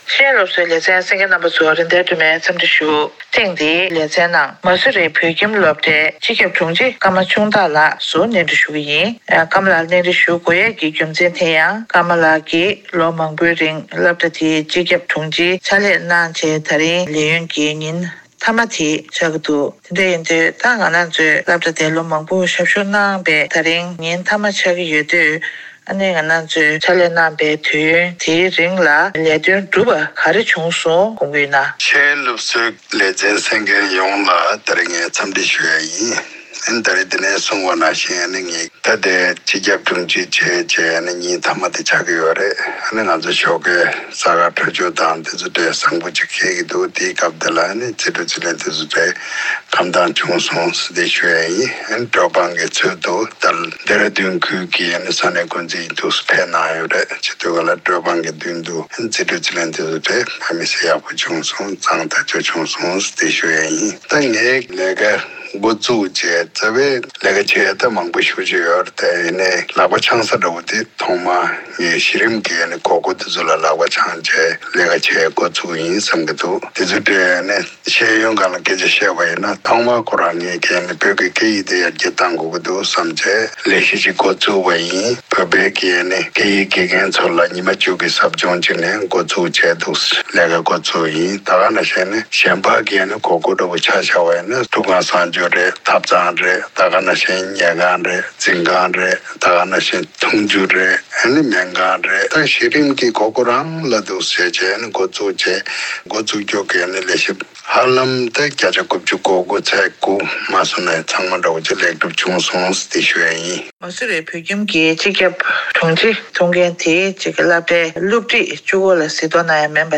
དམིགས་བསལ་གནས་འདྲི